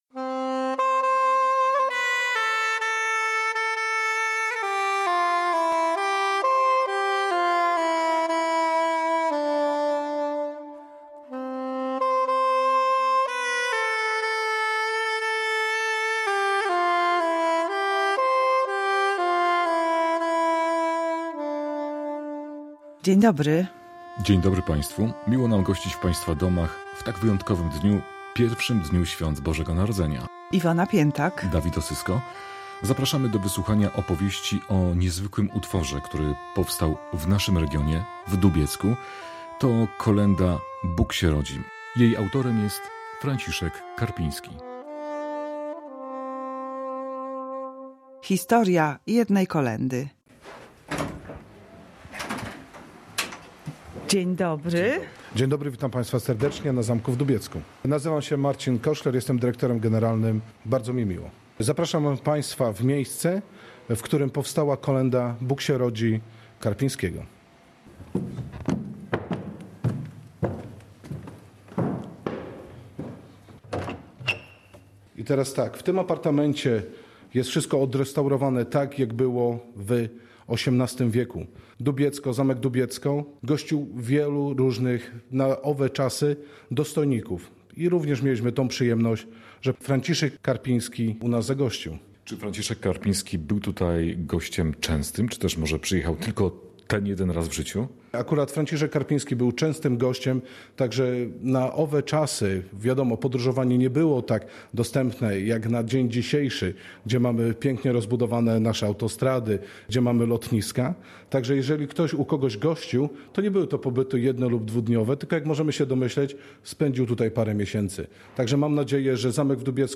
W reportażu „Historia jednej kolędy” odwiedziliśmy Zamek w Dubiecku.